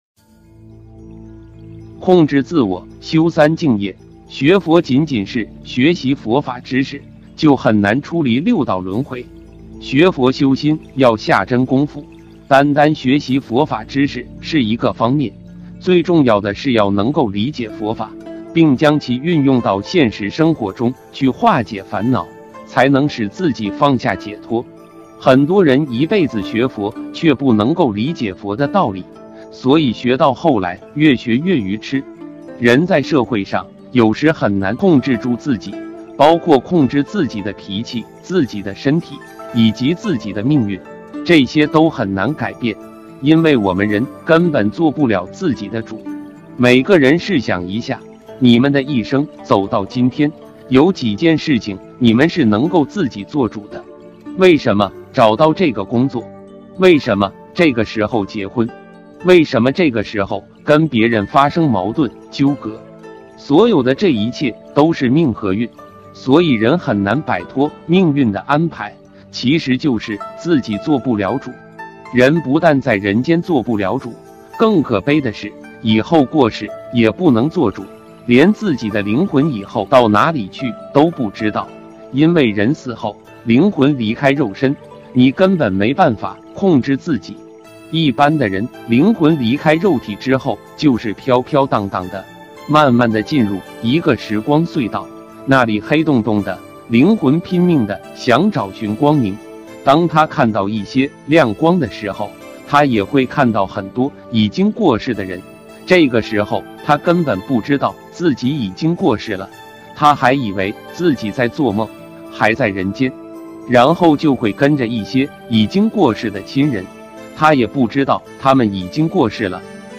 音频：控制自我 修三净业——讲述于观音堂 2020-07-20